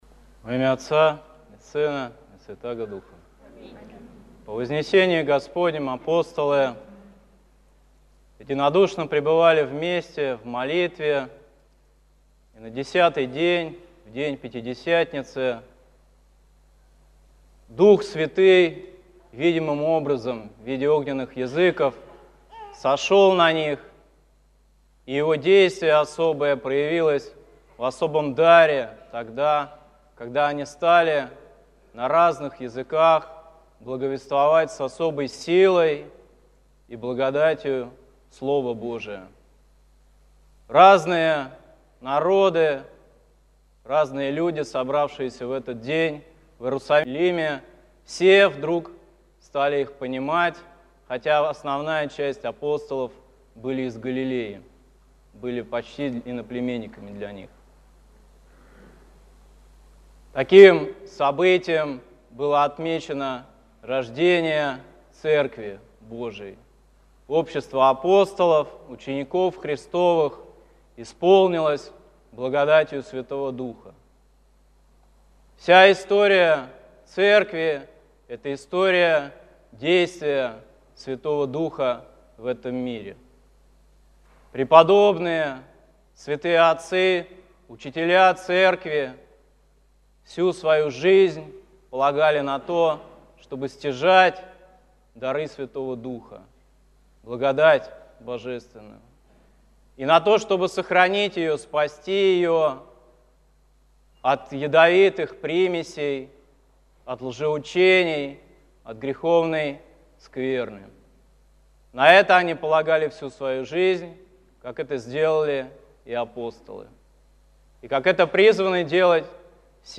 С праздником Святой Троицы!С днем Святого Духа!Спасибо за проповедь!